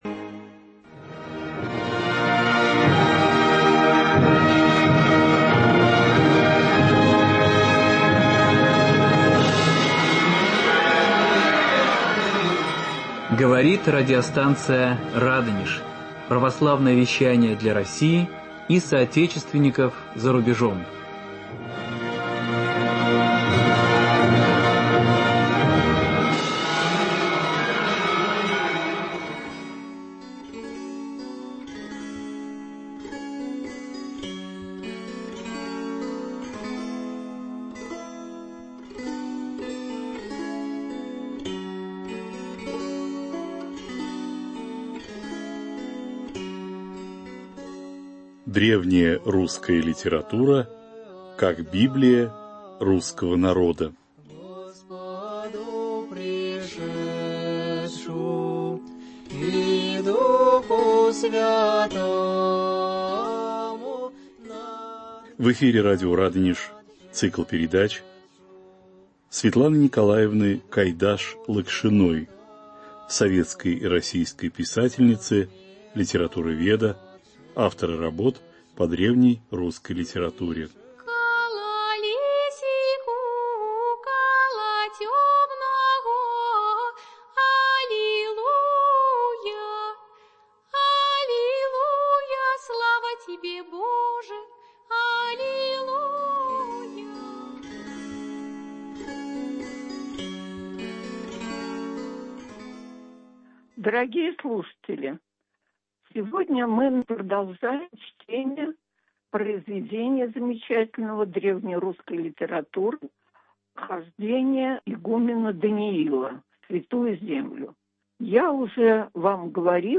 Память их в род и род: беседа